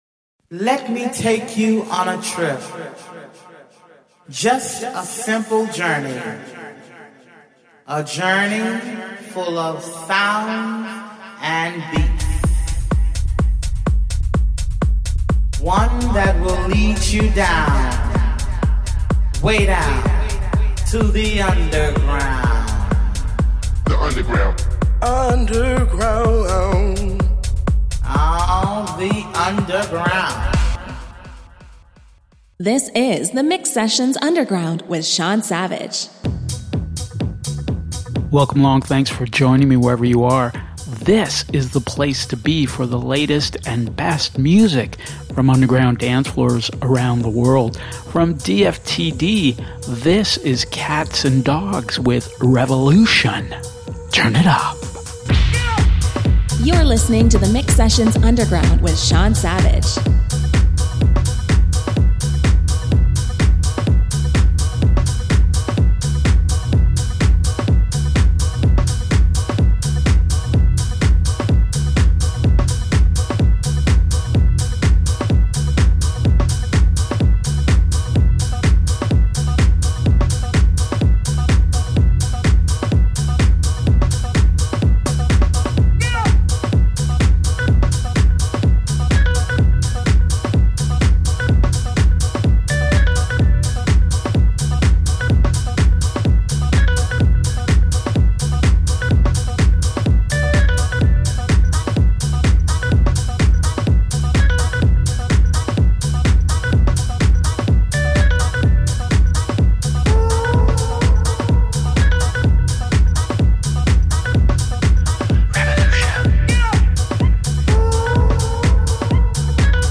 Underground Deep House and Techno